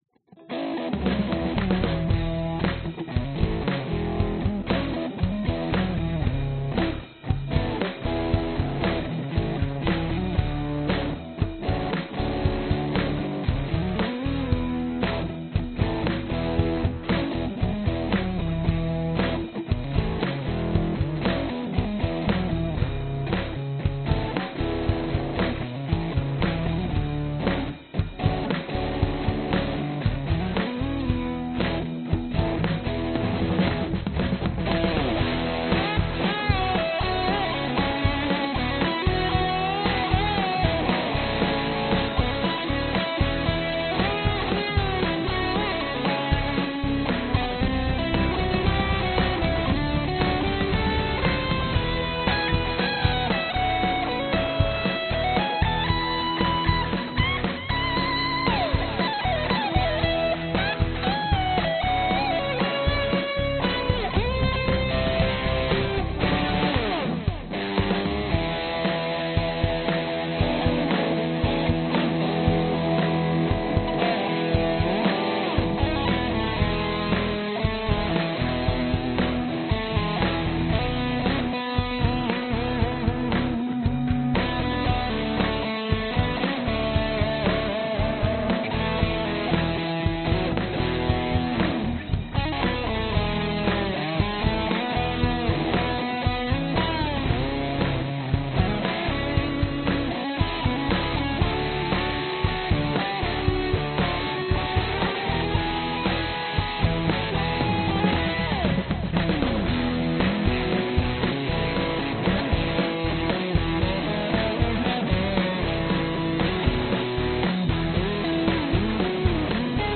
Tag: 贝斯 吉他 器乐 摇滚